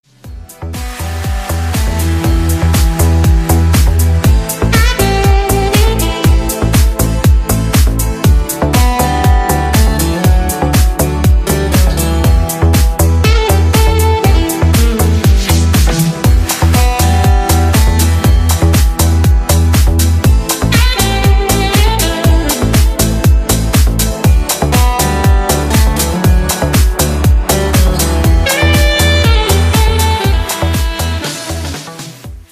• Качество: 320, Stereo
без слов
инструментальные
Саксофон
клубная музыка
Клубный ремикс на хит прошлых лет.